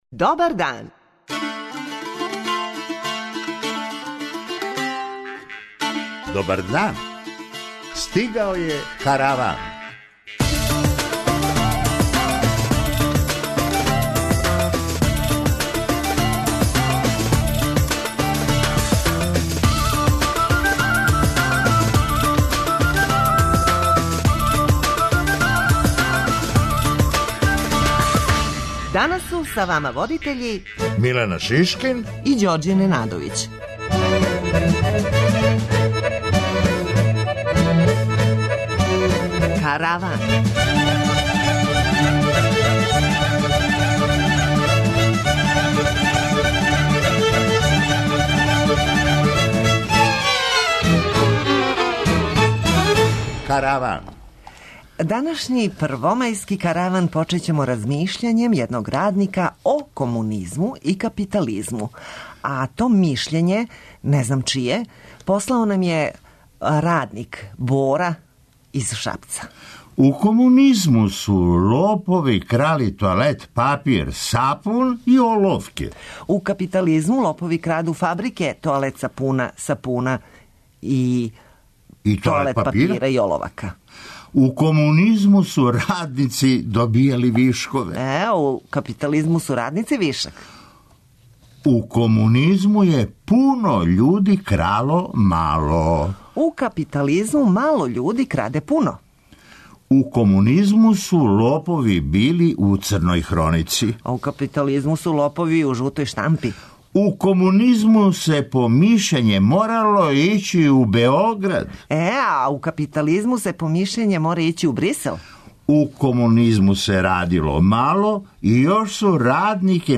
преузми : 24.16 MB Караван Autor: Забавна редакција Радио Бeограда 1 Караван се креће ка својој дестинацији већ више од 50 година, увек добро натоварен актуелним хумором и изворним народним песмама. [ детаљније ] Све епизоде серијала Аудио подкаст Радио Београд 1 Подстицаји у сточарству - шта доносе нове мере Хумористичка емисија Хумористичка емисија Корак ка науци Афера Епстин "не пушта" британског премијера